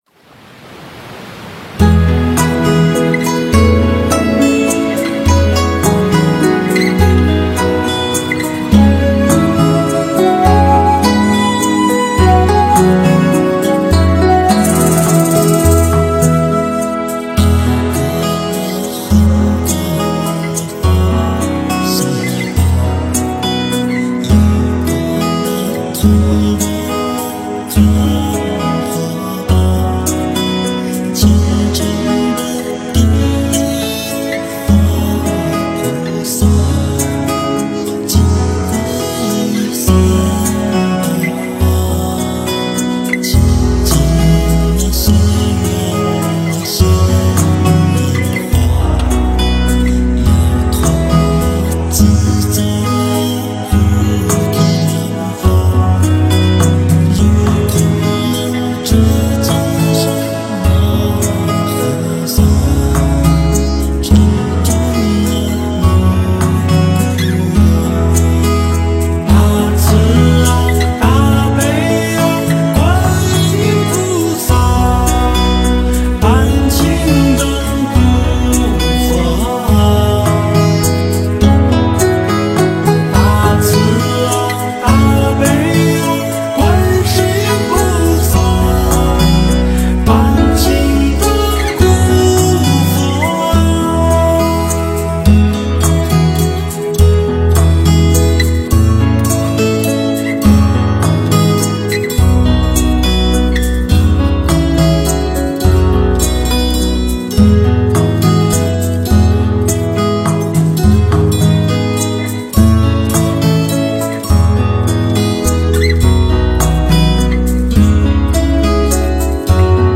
佛音 冥想 佛教音乐 返回列表 上一篇： 毛毛虫也会变蝴蝶(音乐